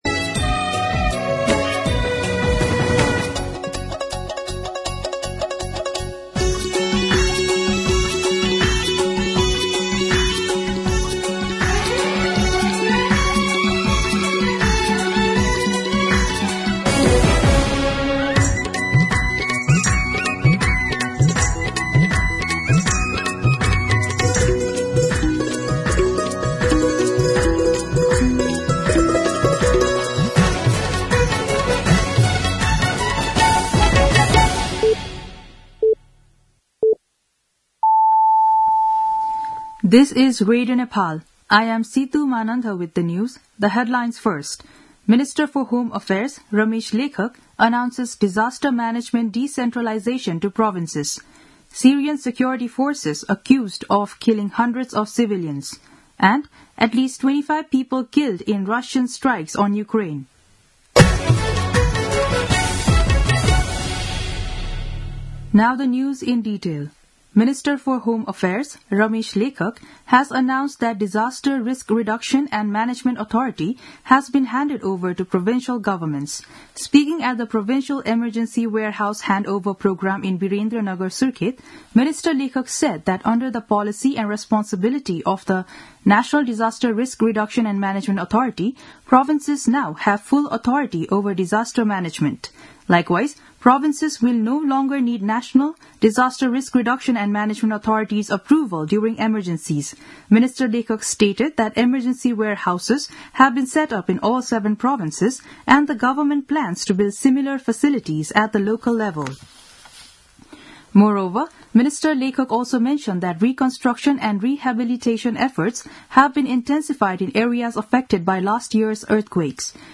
दिउँसो २ बजेको अङ्ग्रेजी समाचार : २६ फागुन , २०८१